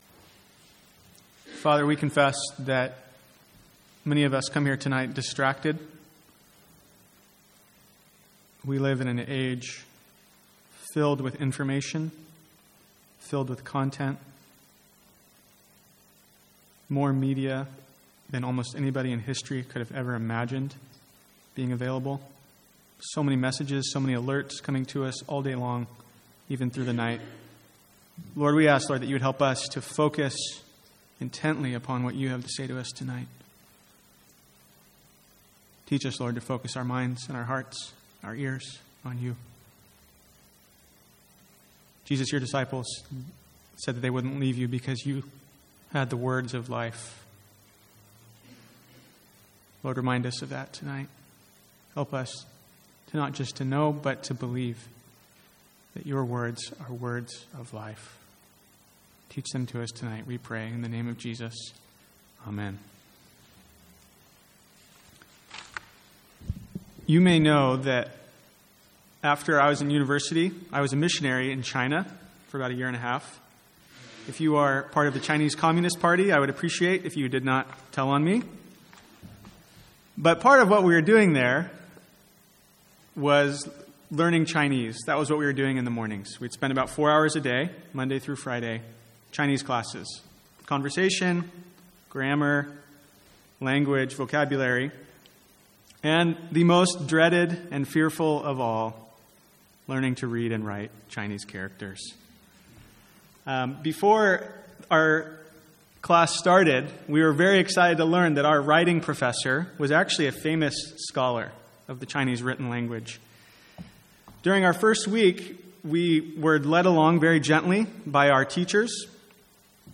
Sermons | St Andrews Free Church
From the Sunday evening series in 1 Corinthians.